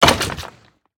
Minecraft Version Minecraft Version snapshot Latest Release | Latest Snapshot snapshot / assets / minecraft / sounds / mob / wither_skeleton / hurt4.ogg Compare With Compare With Latest Release | Latest Snapshot